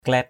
/klɛ:t/ (t.) trẹt = peu profond, plat. mbaok klait _O<K =k*T mặt trẹt = visage aplati. la-i klait li} =k*T thúng trẹt = corbeille peu profonde. jam klait j’ =k*T...
klait.mp3